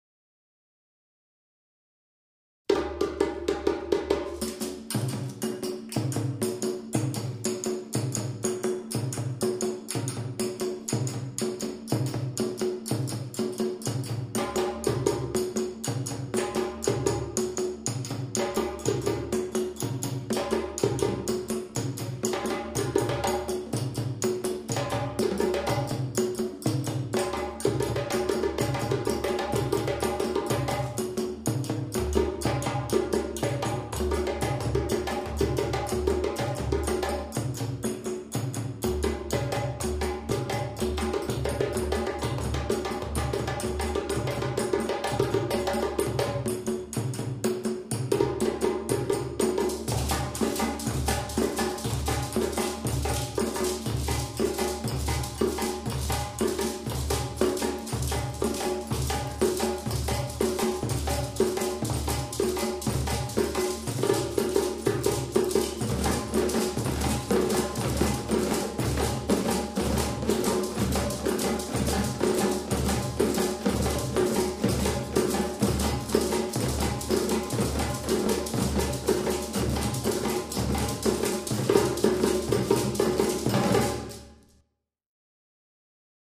gespielt von der 4b Josefschule, Oberhausen